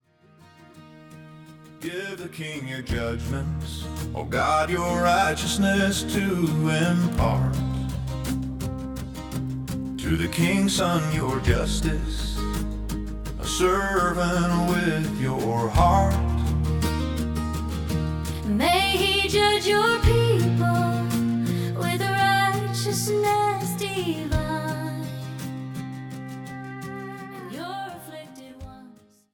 authentic Country worship
the authentic sound of modern Country worship
From intimate acoustic moments to full-band celebrations